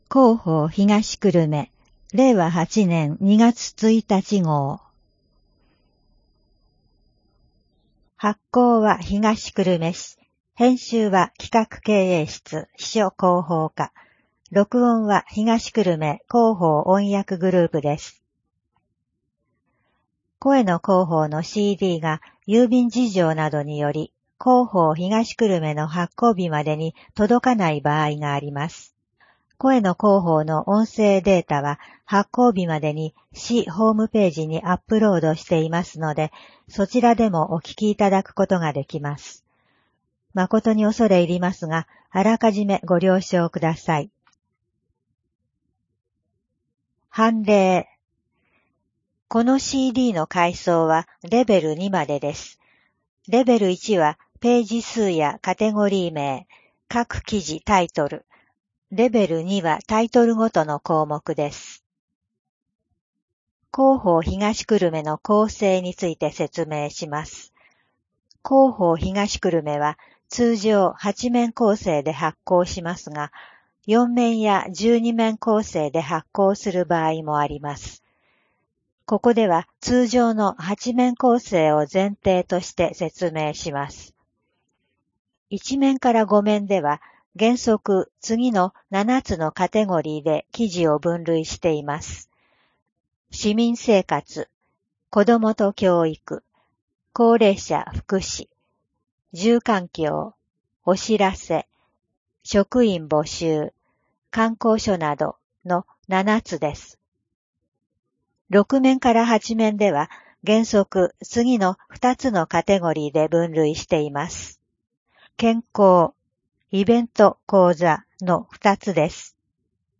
声の広報（令和8年2月1日号）